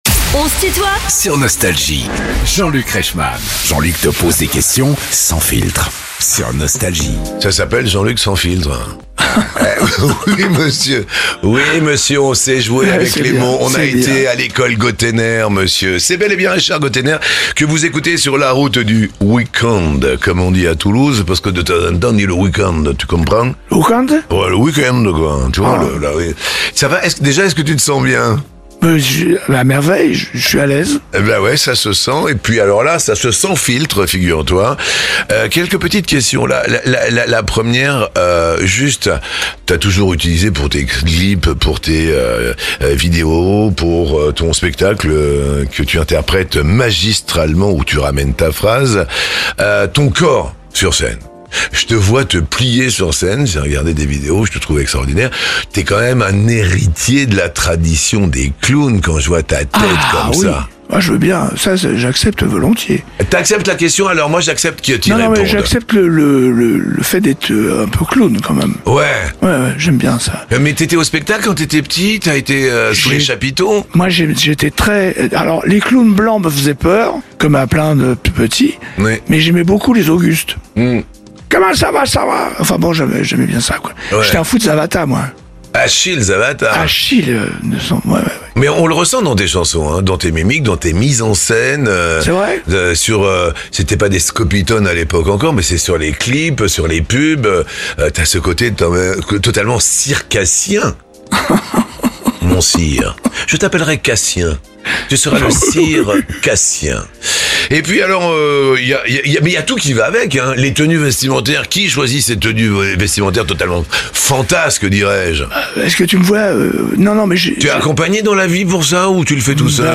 Invité de "On se tutoie ?...", Richard Gotainer répond aux questions sans filtre de Jean-Luc Reichmann
Les interviews
Les plus grands artistes sont en interview sur Nostalgie.